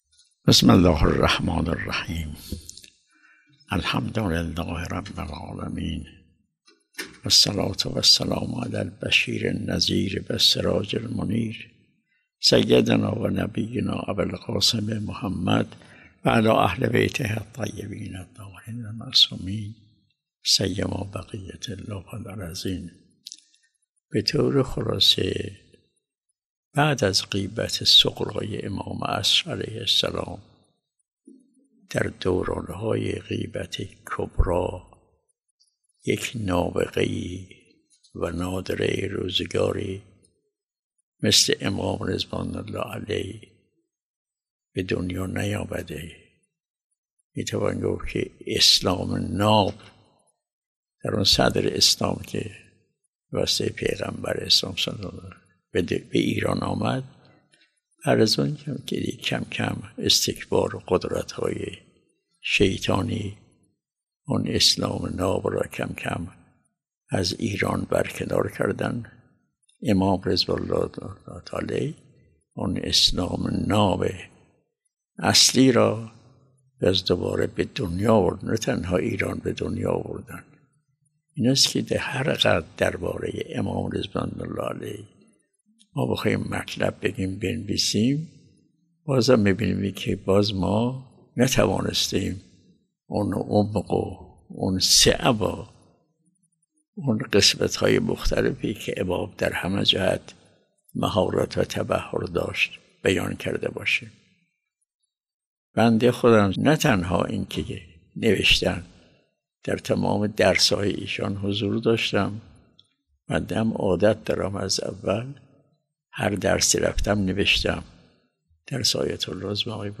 به گزارش خبرنگار سرویس سیاسی خبرگزاری رسا، حضرت آیت الله حسین نوری همدانی در مراسم سالگرد ارتحال امام خمینی که در دفتر این مرجع تقلید برگزار شد، امام خمینی را فردی نابغه و نادر پس از غیبت صغری دانست و افزود: اسلام نابی که در صدر اسلام به دست پیامبر اکرم (ص) به ایران راه یافت، به‌تدریج توسط استکبار و قدرت‌های شیطانی از صحنه کنار زده شد و می توان گفت این امام راحل بود که آن اسلام اصیل را نه تنها در ایران، بلکه در سراسر جهان احیا کرد.
جهت دانلود صوت بیانات آیت الله نوری همدانی کلیک کنید